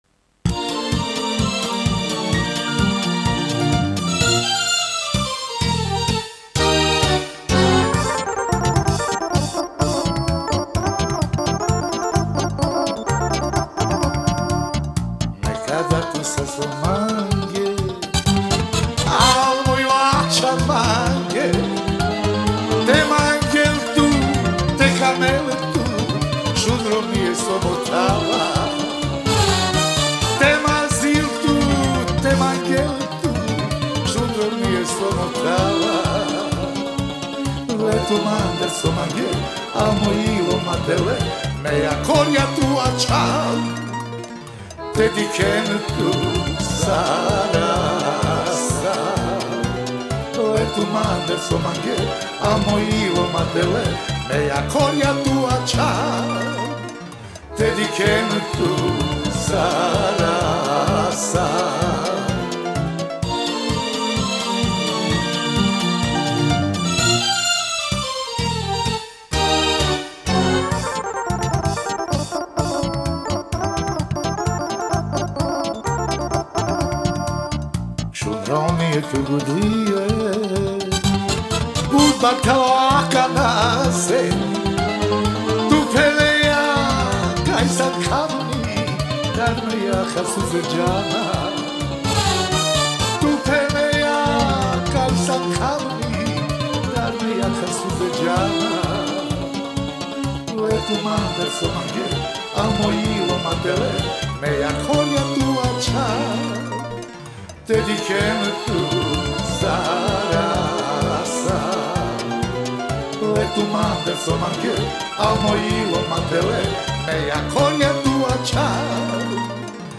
Genre: Gypsy Jazz, World, Ethnic, Folk Balkan